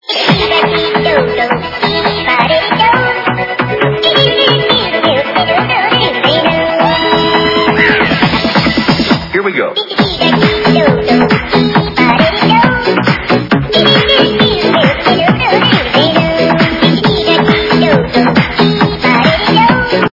- Remix
При заказе вы получаете реалтон без искажений.